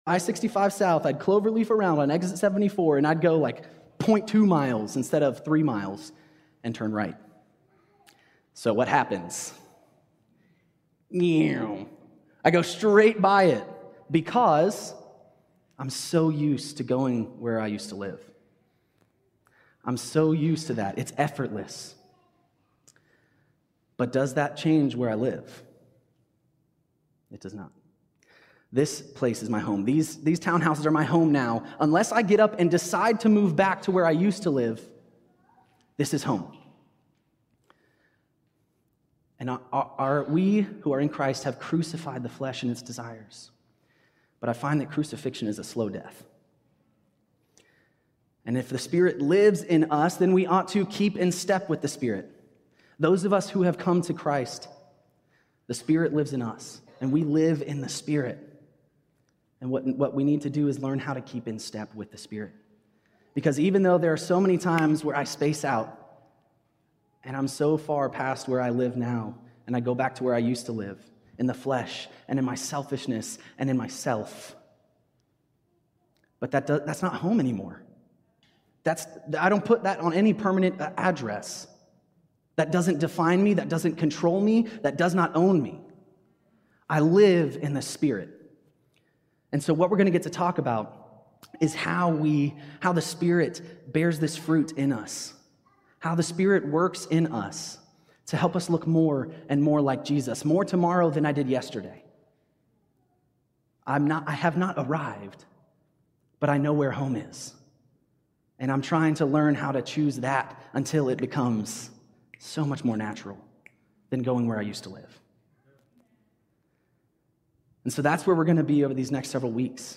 This sermon began our series on The Fruit of the Spirit. Due to some technical issues, the first 5 minutes of the sermon were unavailable.